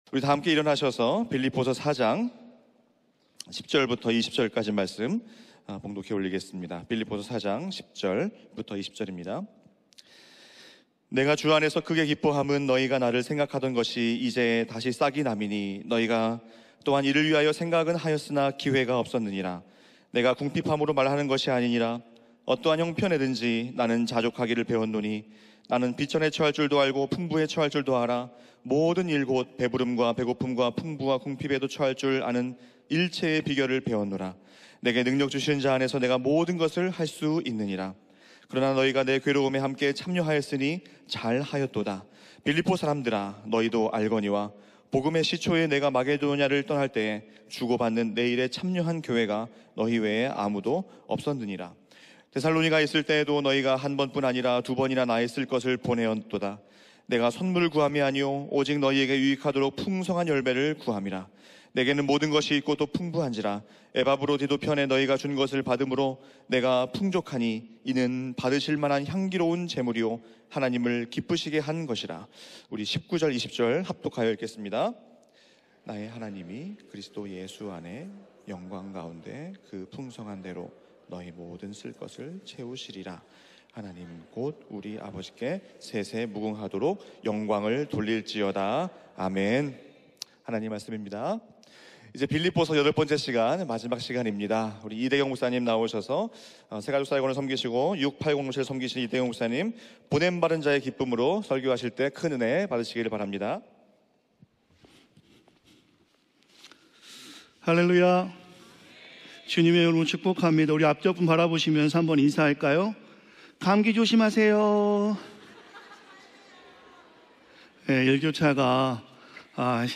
예배: 토요 새벽